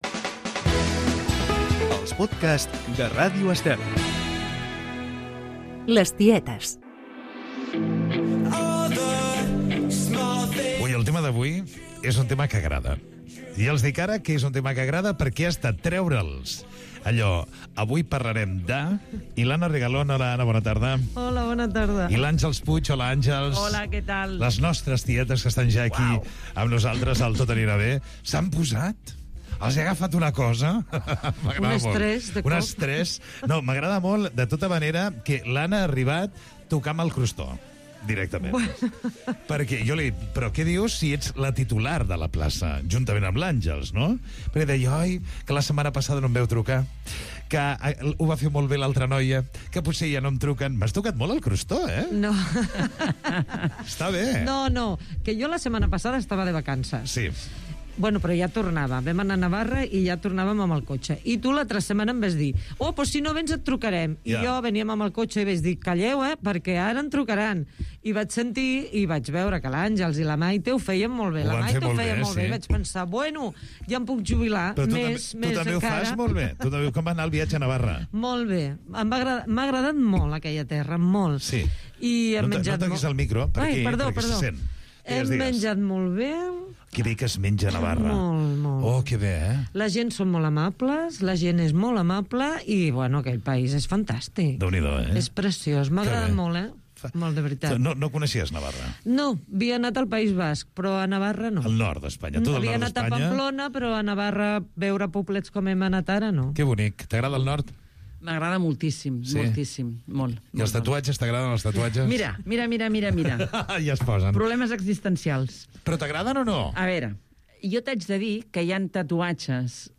unes senyores de 60 anys